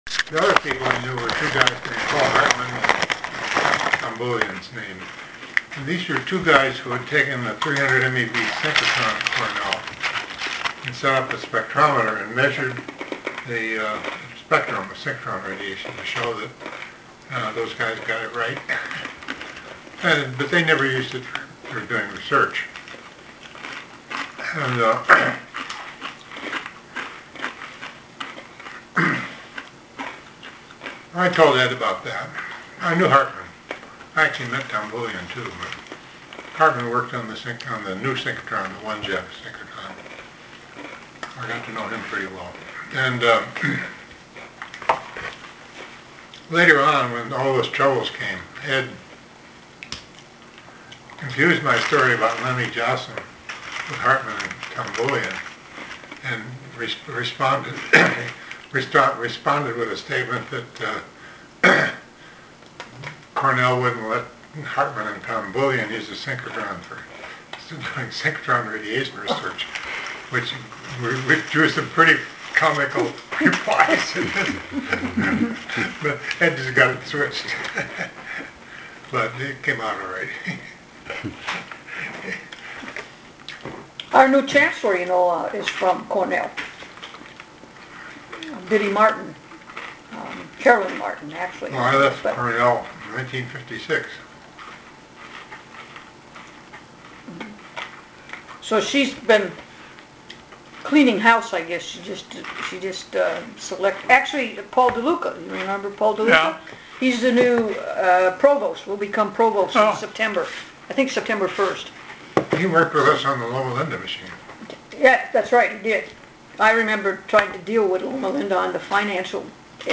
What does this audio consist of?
Recording, oral